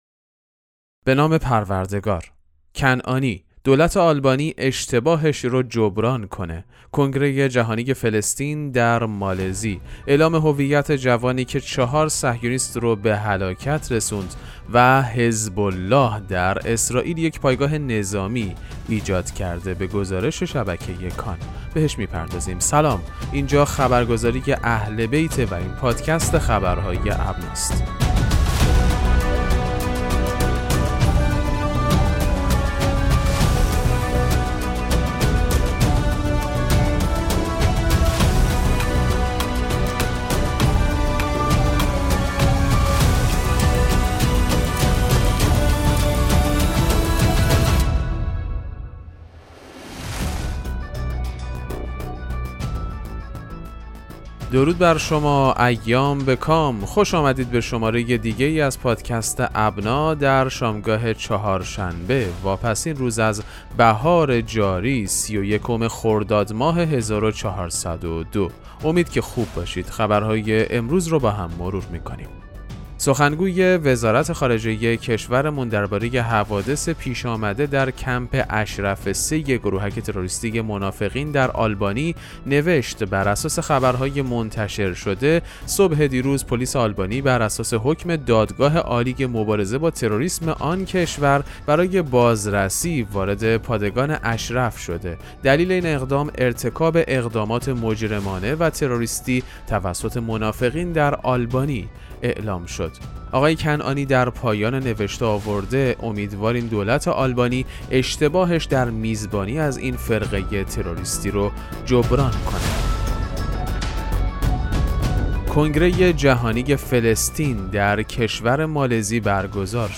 پادکست مهم‌ترین اخبار ابنا فارسی ــ 31 خرداد 1402